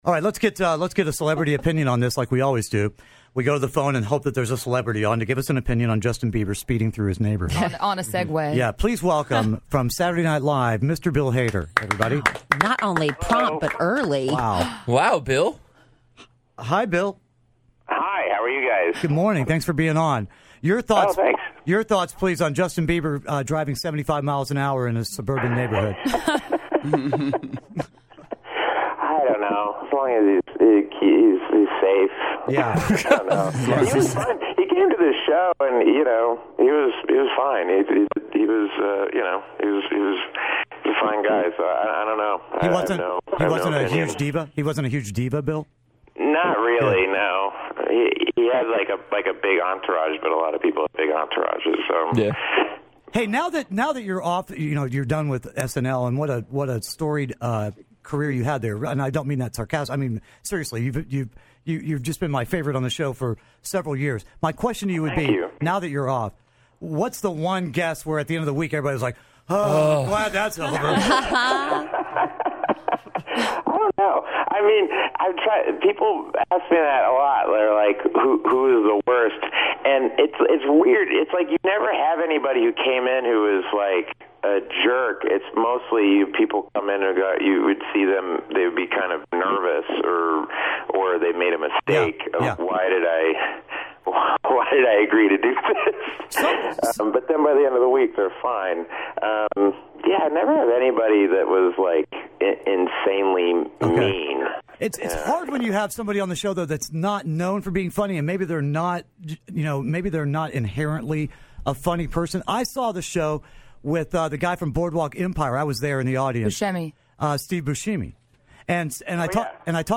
Kidd Kraddick in the Morning have a phone interview with Bill Hader!